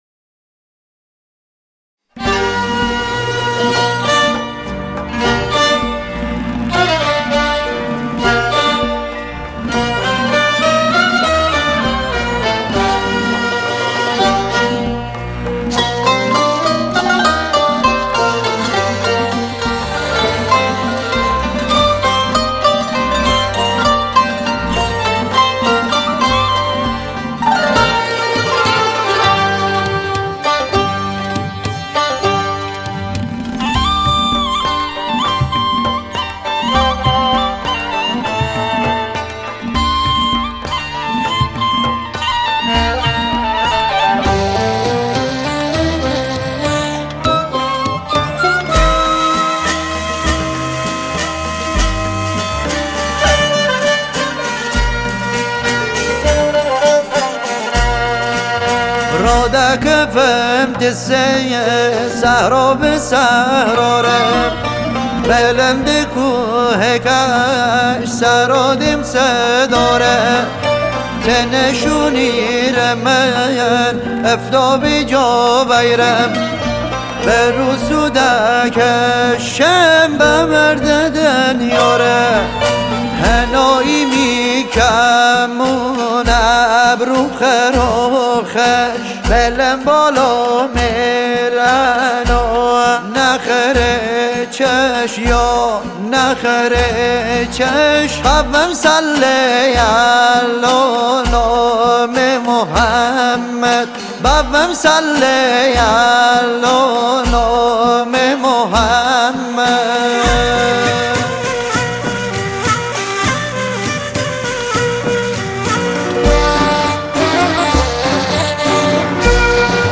سنتی
آهنگ سنتی مازندرانی